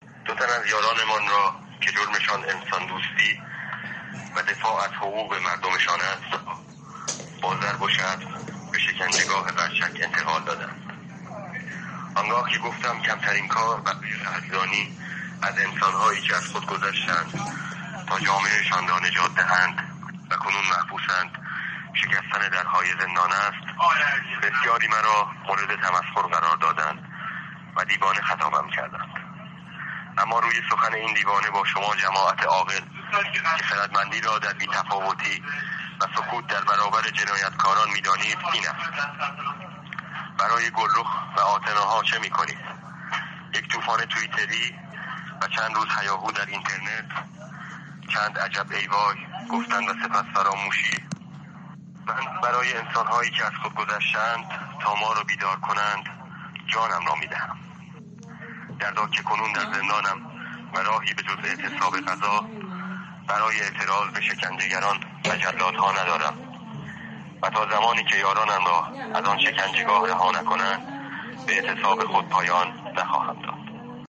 از زندان اوین